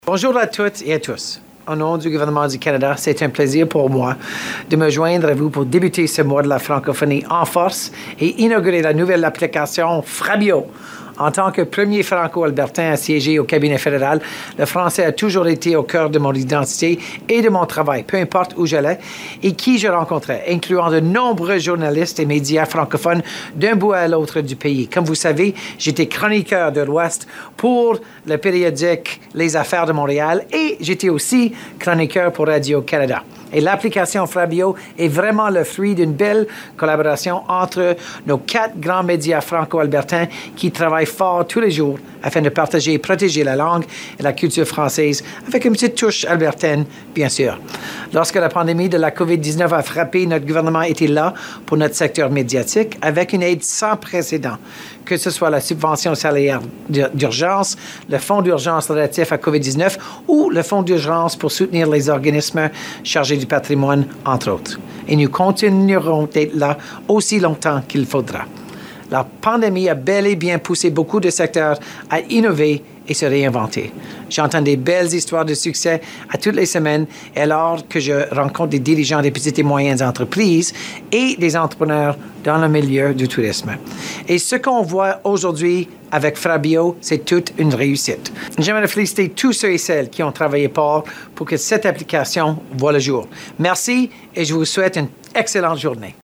Pour l'occasion, le ministre fédéral du Tourisme Randy Boissonneault a glissé un mot de façon virtuelle.
Vous pouvez écouter la déclaration de Randy Boissonneault, ministre fédéral du Tourisme, ici :